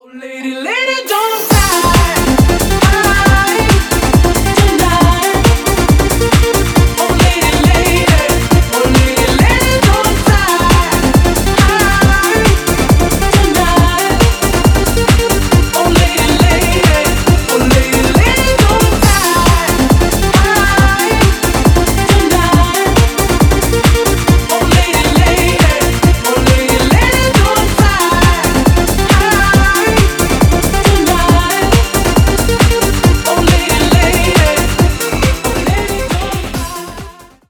Ремикс
Танцевальные